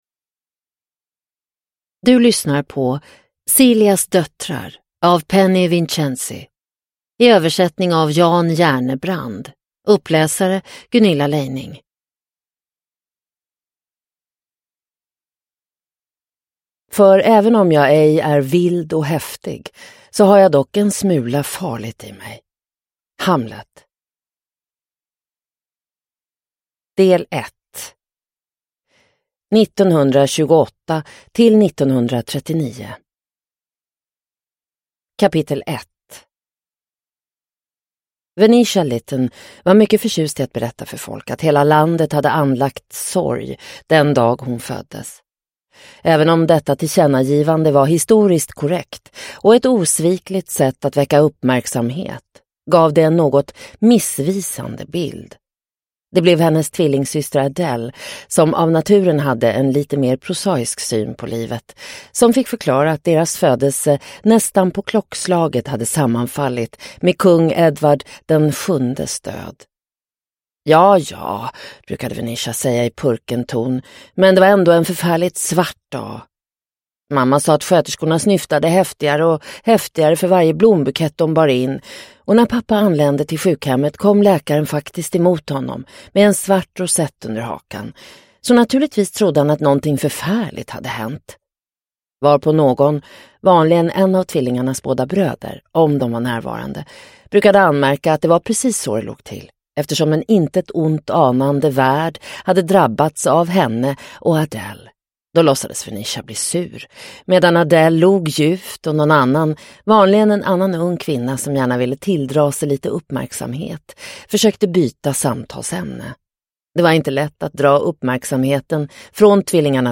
Celias döttrar – Ljudbok – Laddas ner